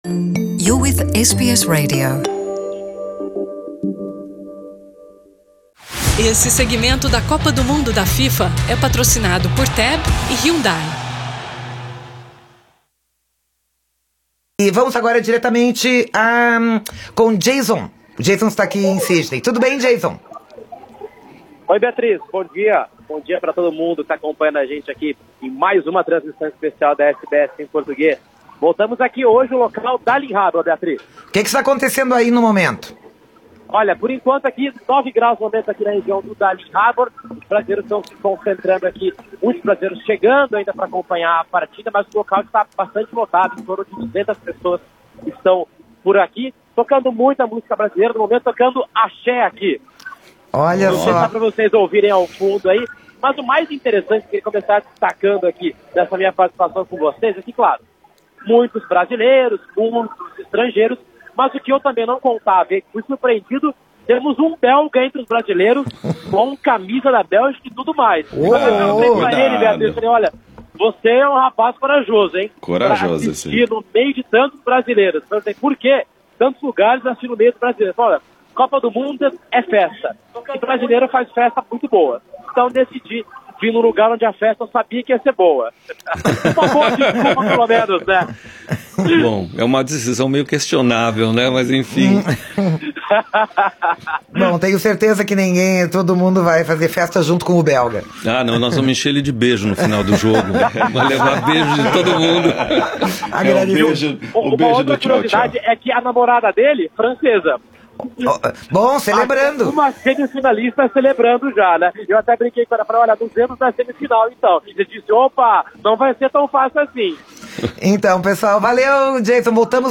Muita tristeza por parte dos brasileiros que foram ao Darling Harbour.